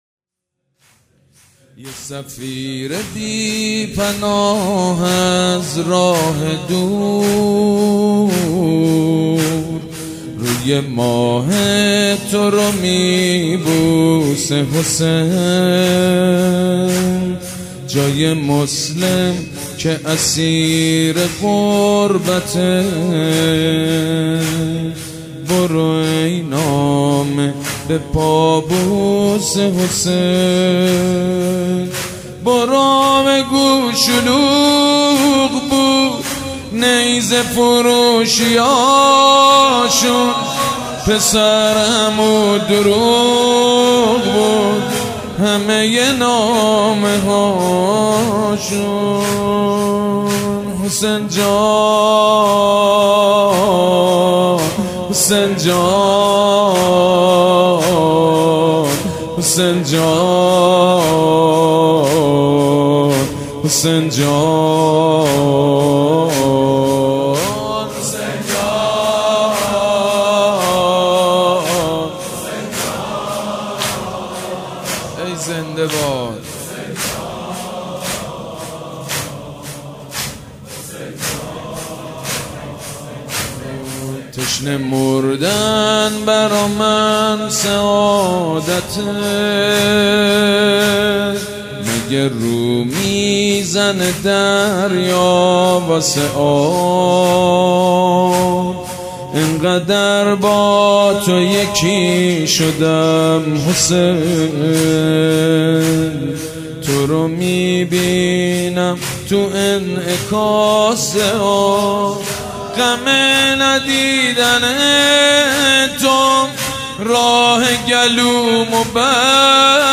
یه سفیر بی پناه با صدای سیدمجید بنی فاطمه (فیلم، صوت، متن)
نوحه زمینه یه سفیر بی پناه با صدای حاج سید مجید بنی فاطمه، ویژه شهادت جناب مسلم ابن عقیل (علیه السلام)، اول شهید راه حسین، هیئت ریحانه الحسین علیه السلام - ایام مسلمیه 1400 ه.ش